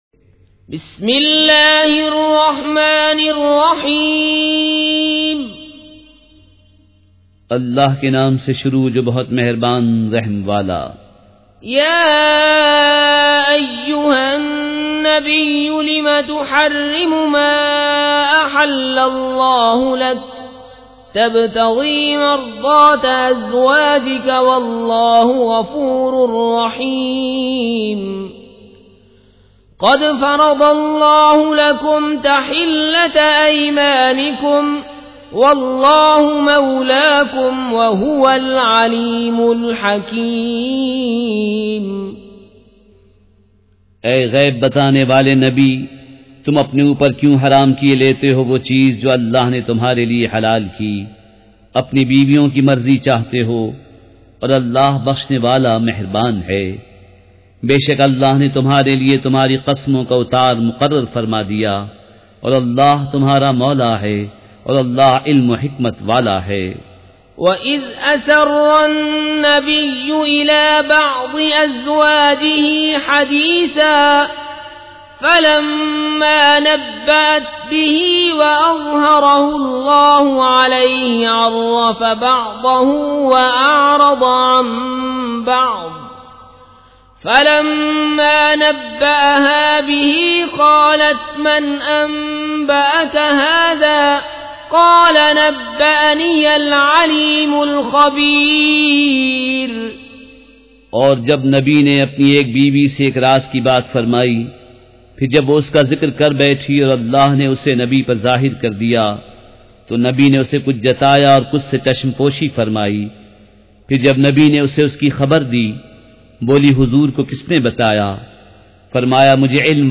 سورۃ التحریم مع ترجمہ کنزالایمان ZiaeTaiba Audio میڈیا کی معلومات نام سورۃ التحریم مع ترجمہ کنزالایمان موضوع تلاوت آواز دیگر زبان عربی کل نتائج 1666 قسم آڈیو ڈاؤن لوڈ MP 3 ڈاؤن لوڈ MP 4 متعلقہ تجویزوآراء